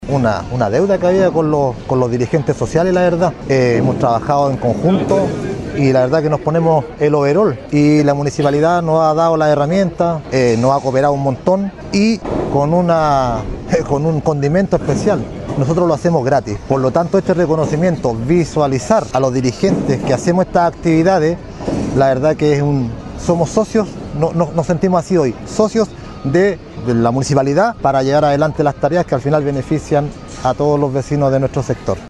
Cientos de asistentes llegaron el viernes último al frontis de la Municipalidad de Pucón, en donde prácticamente todas las fuerzas vivas de la urbe lacustre se reunieron para festejar los 143 años de existencia de esta reconocida urbe turística en un desfile cívico, del que fueron parte más de 40 organizaciones.